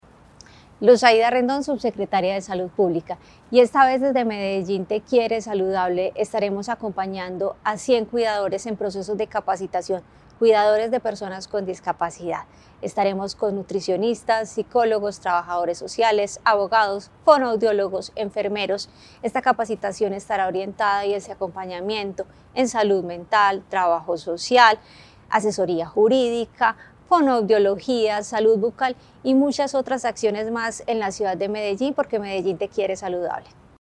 Audio Declaraciones de la subsecretaria de Salud, Luz Aida Rendón
Audio-Declaraciones-de-la-subsecretaria-de-Salud-Luz-Aida-Rendon.mp3